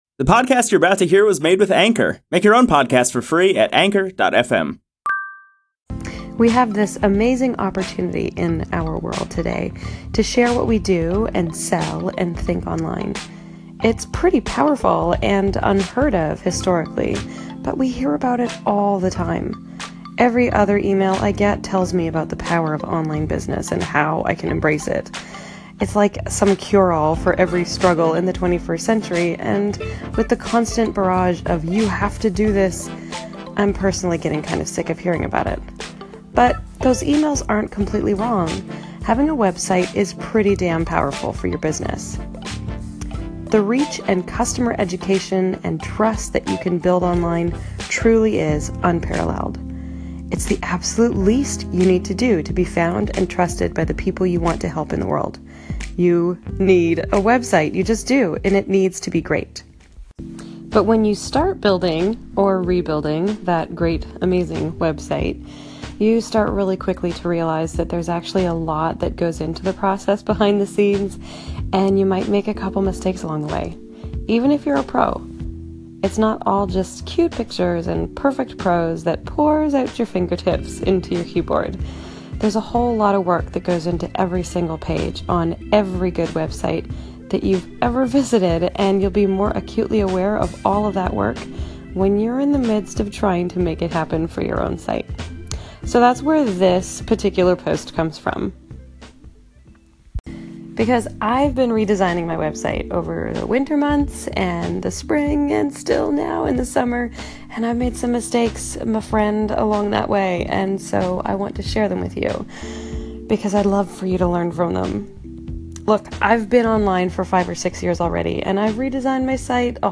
Want to listen to my gravelly-ass Voice instead?